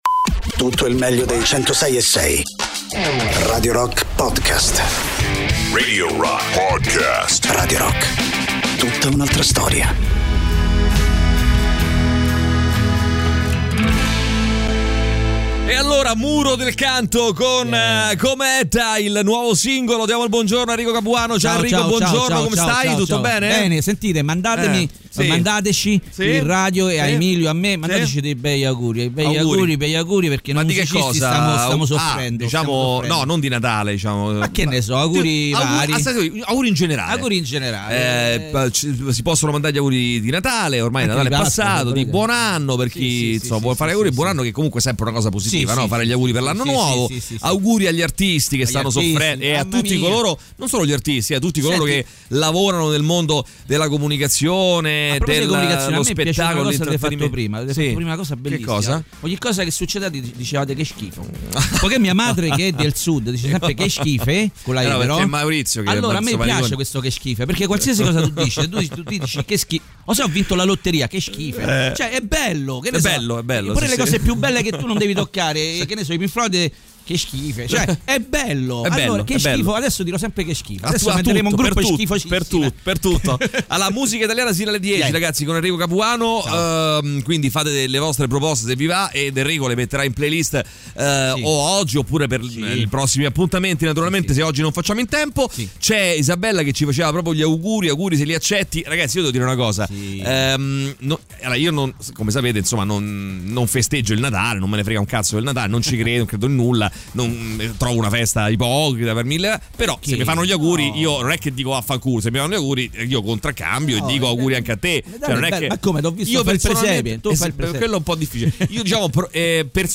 ospite in studio